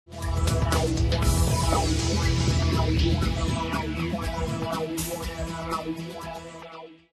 powerdown_telstra.aac